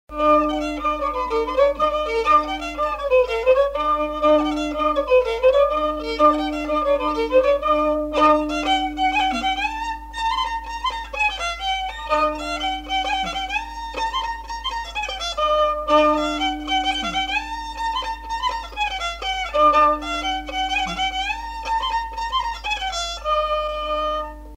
Rondeau
Aire culturelle : Lomagne
Lieu : Garganvillar
Genre : morceau instrumental
Instrument de musique : violon